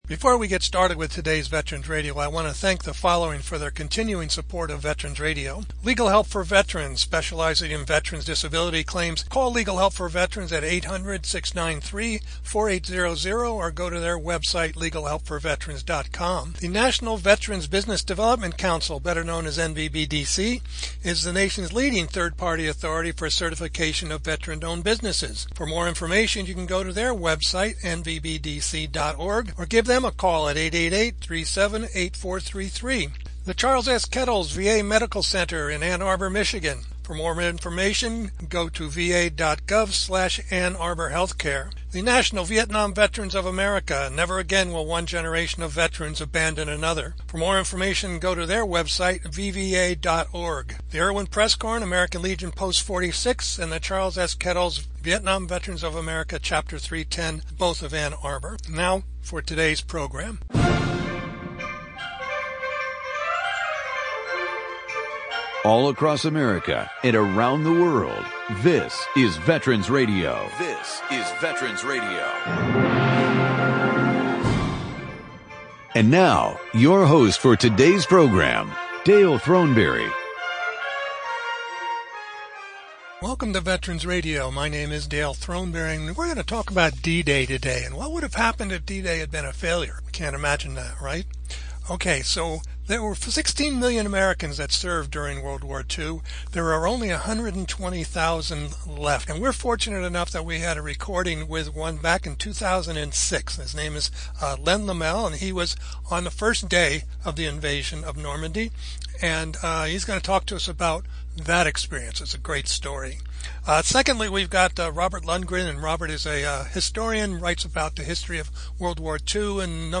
June 16, 2024: Remembering D-Day with Interviews from the Archives